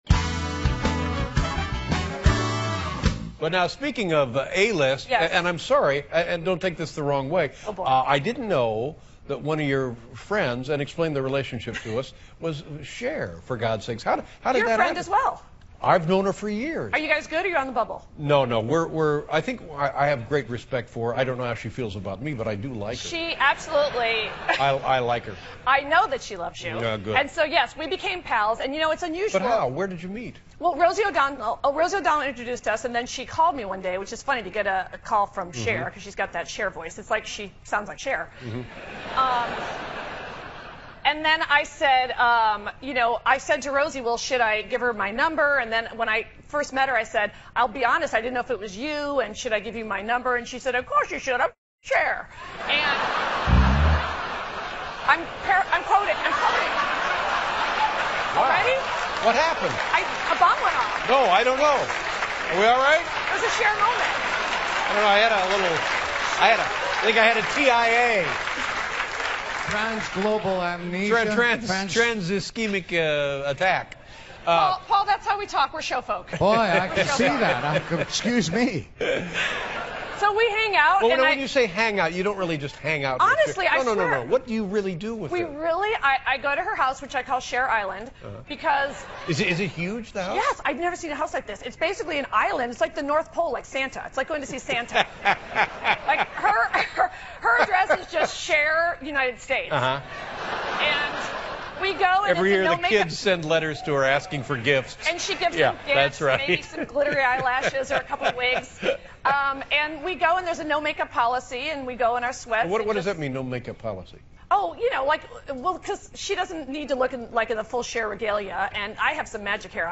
访谈录 2011-06-09&06-11 常青树雪儿专访 听力文件下载—在线英语听力室